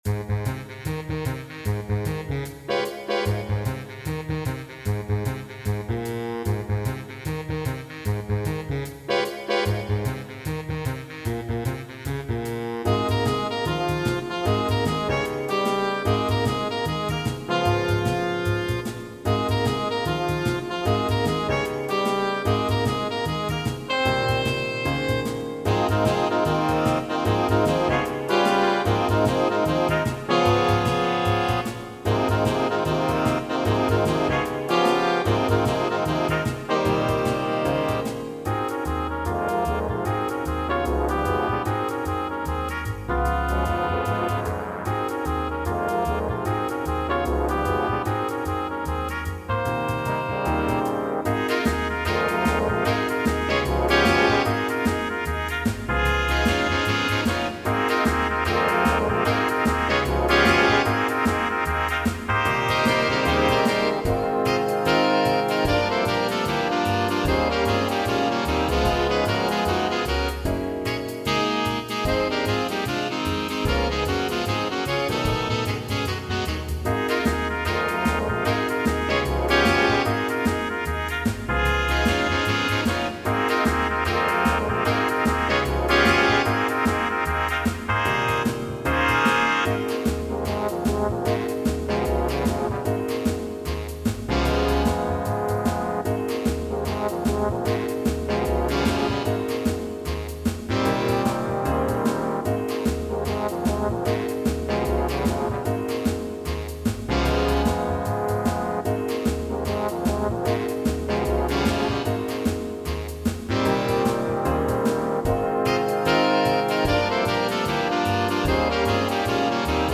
Big Band
All audio files are computer-generated.
A shuffle-style tune (tempo = 150) with tenor sax, trumpet and trombone improvised solos and a written bass solo. Piano requires comping.